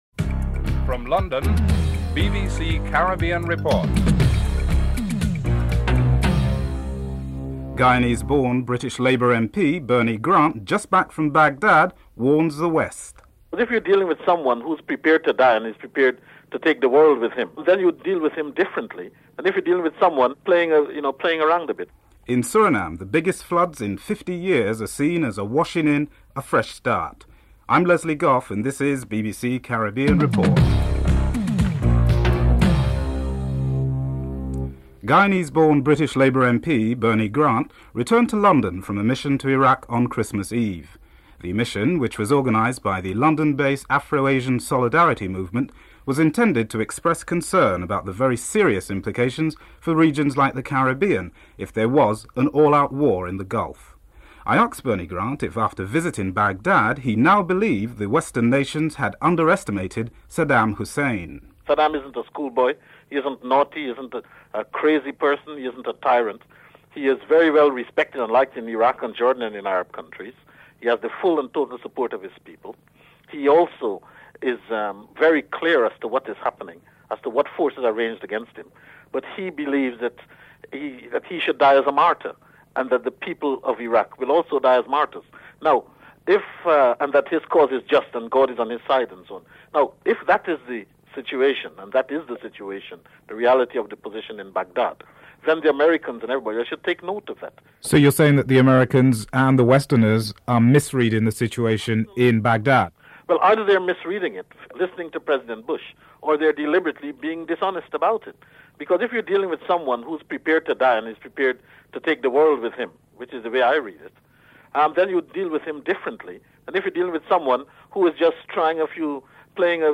In this segment, Guyanese born, British Labour Party MP, Mr. Bernie Grant speaks about his recent visit to Iraq and explains the serious implications that the Caribbean regions face should a war occur in the Gulf. A report from Suriname explains the ‘coup by telephone’ which was conducted by the military and how one of the biggest floods in 50 years is seen in Suriname as a washing towards a fresh start.
1. Headlines (00:00-00:34)